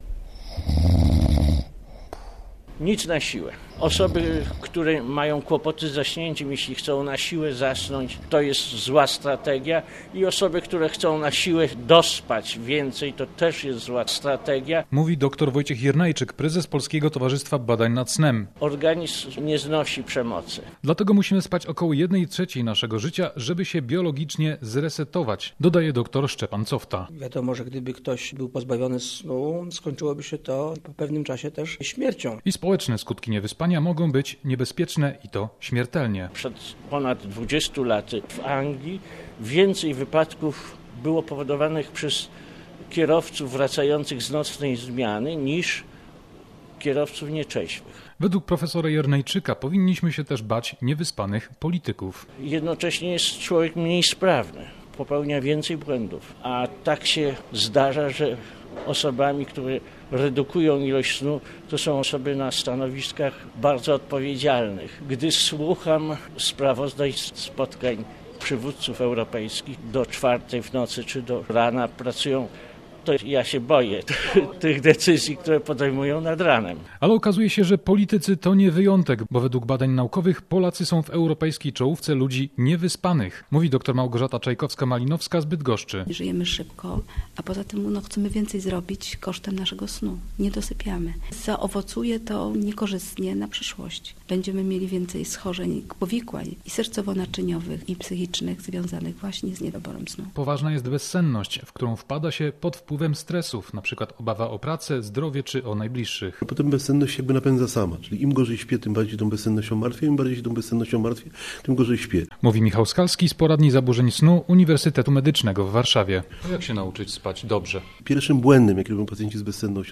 Jakie są społeczne konsekwencje niewyspania? To sprawdzał nasz reporter w Gnieźnie na IX Zjeździe Polskiego Towarzystwa Badań nad Snem.